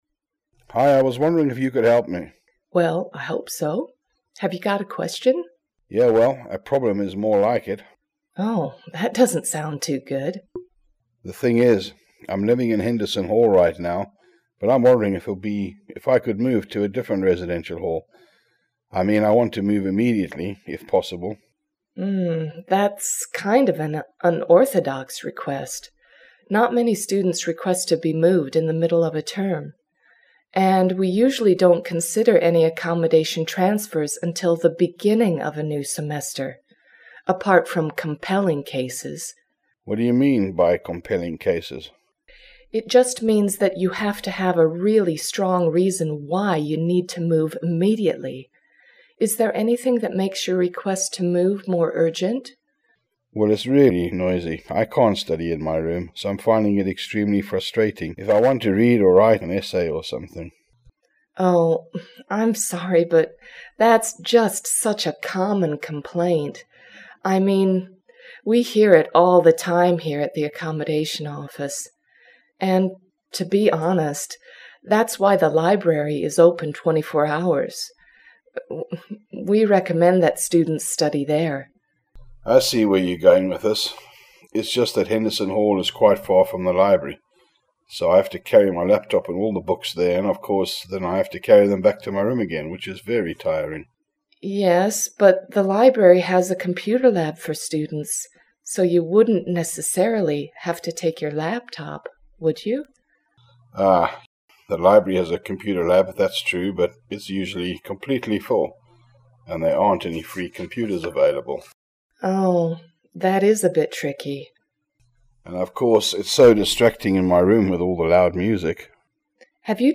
Listen to the following conversation between a student and a member of university staff.